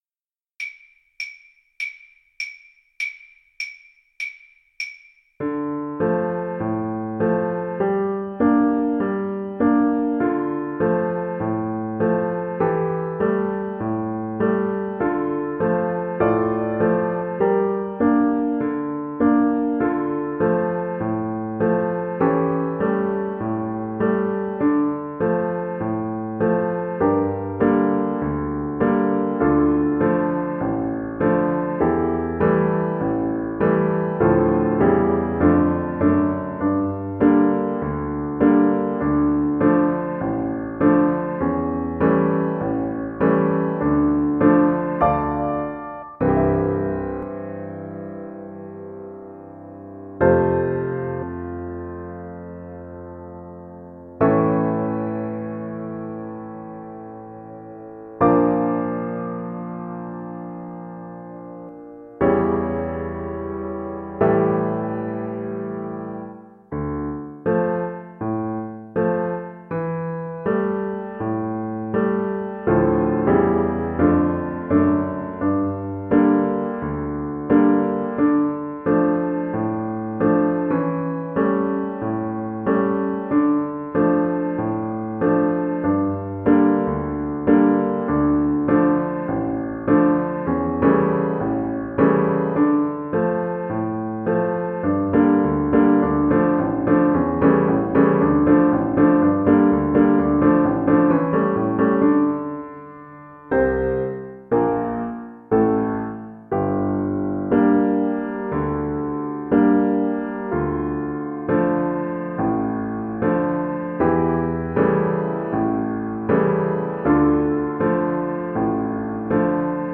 Violin
D minor (Sounding Pitch) (View more D minor Music for Violin )
4/4 (View more 4/4 Music)
Traditional (View more Traditional Violin Music)
Gypsy music for violin
Russian